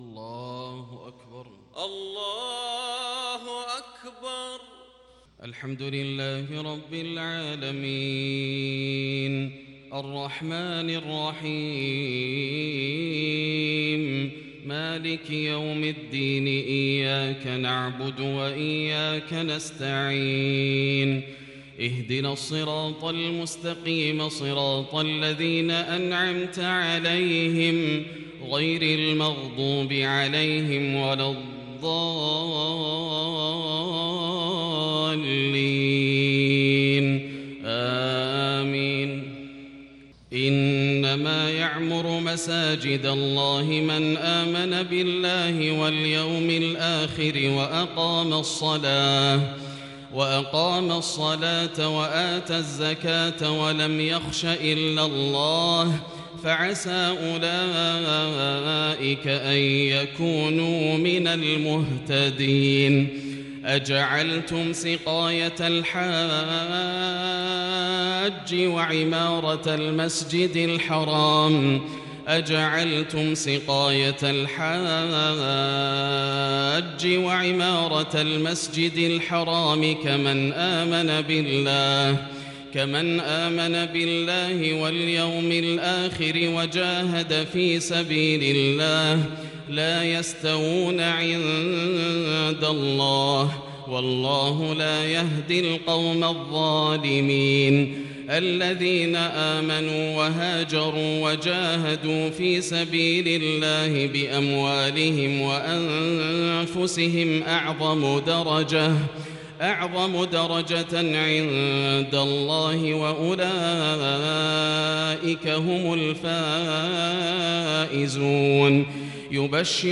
صلاة الفجر للشيخ ياسر الدوسري 16 صفر 1442 هـ
تِلَاوَات الْحَرَمَيْن .